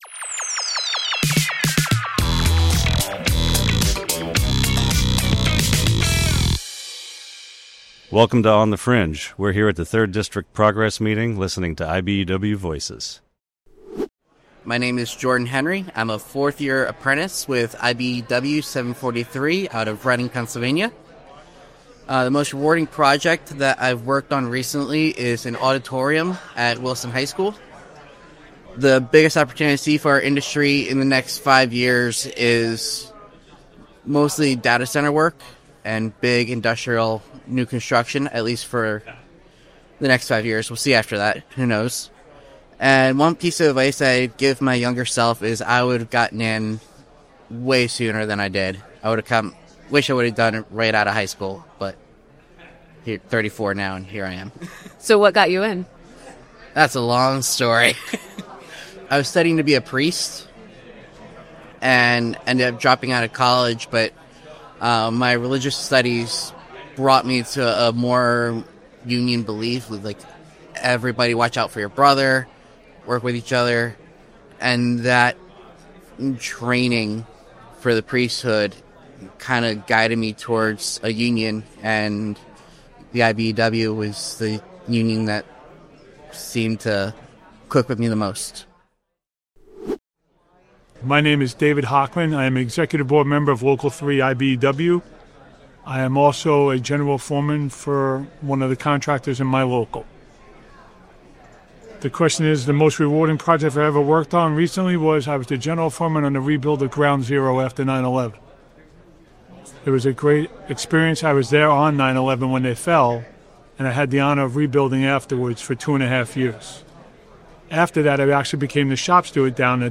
Step inside the 3rd District Progress Meeting and hear directly from the people shaping the future of IBEW.